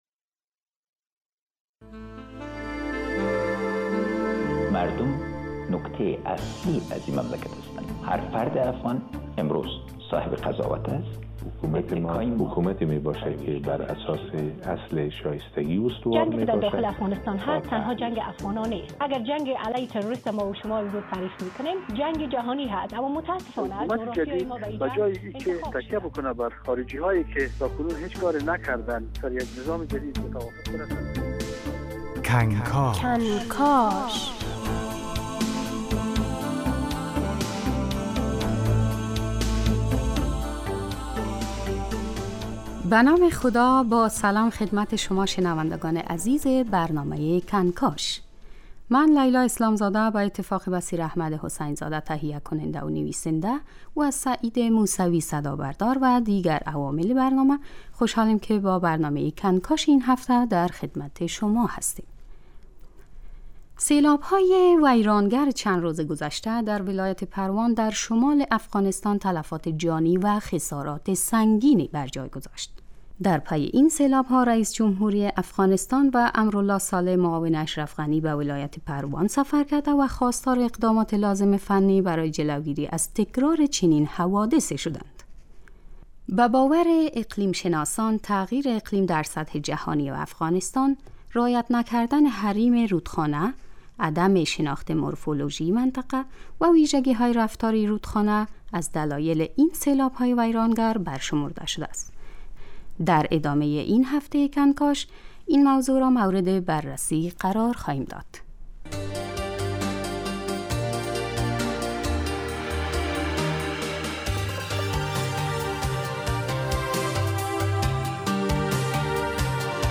بررسی علت سیلاب های اخیر ولایت پروان در شمال افغانستان و گفت و گو با مسئولان و کارشناسان اقیلم شناسی.